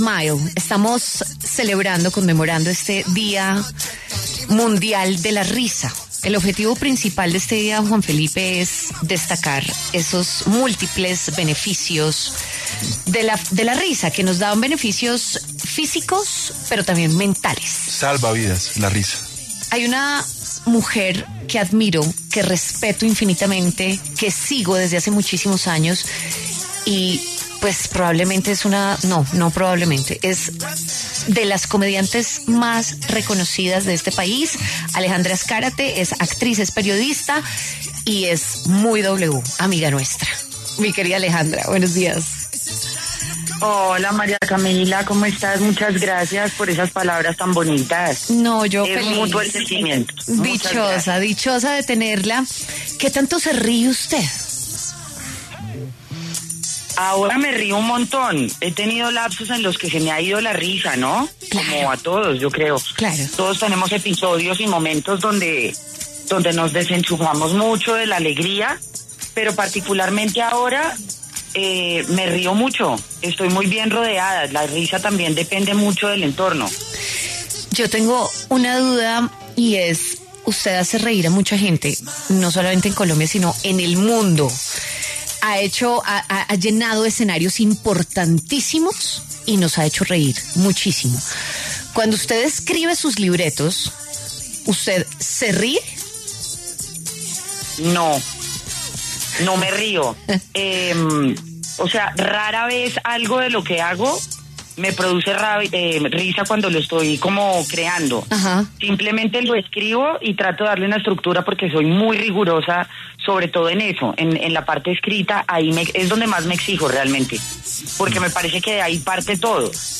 La comediante Alejandra Azcárate habló en W Fin de Semana a propósito del Día Mundial de la Risa.